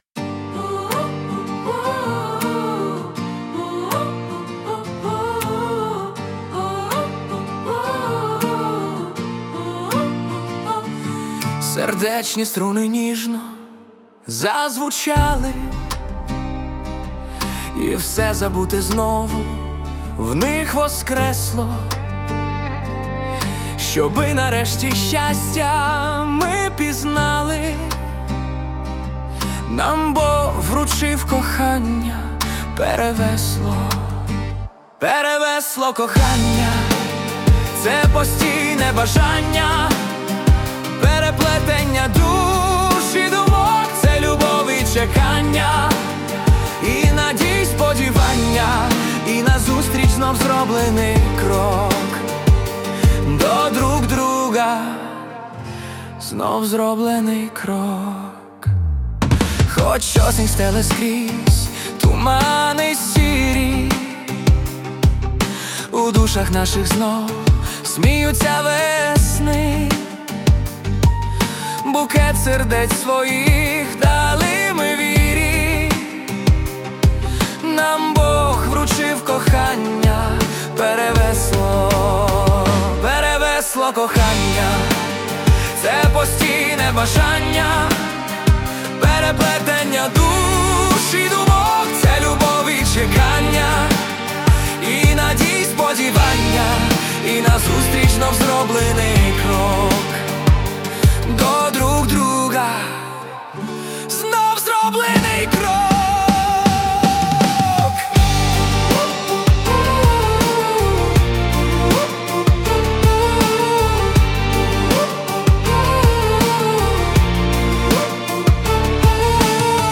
Пісні про кохання, ліричні